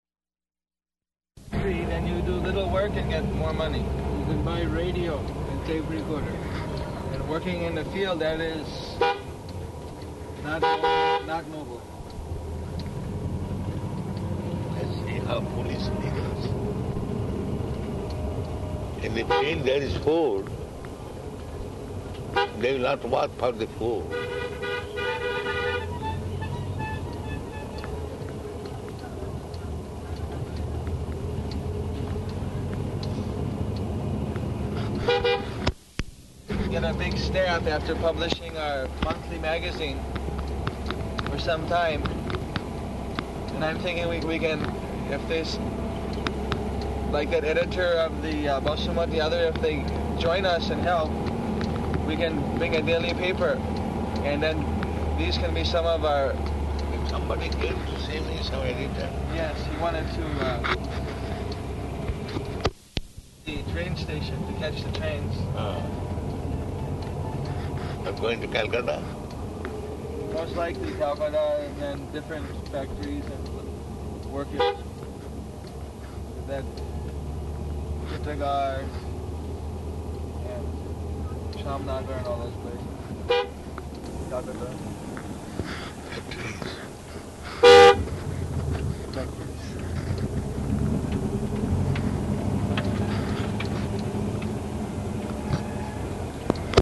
Car Conversation
Location: Calcutta